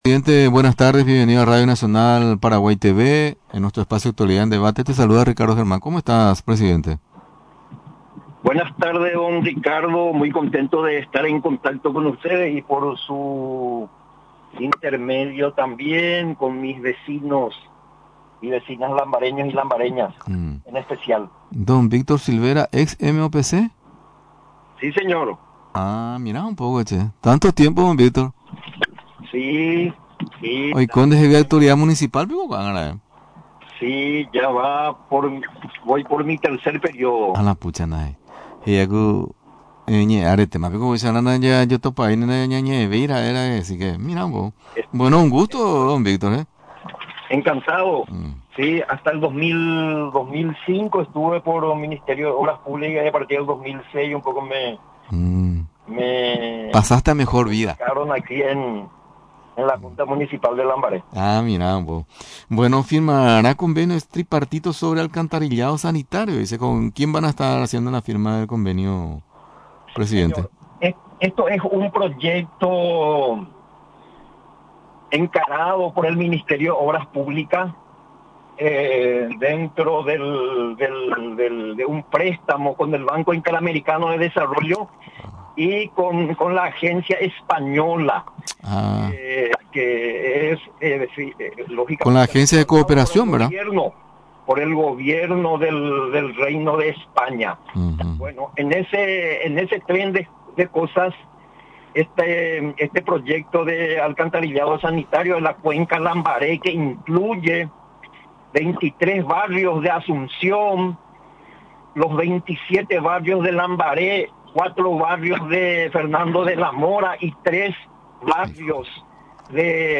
La Municipalidad de Lambaré suscribirá convenio tripartito sobre alcantarillado sanitario, confirmó este martes el presidente de la Junta Municipal, Víctor Silvera.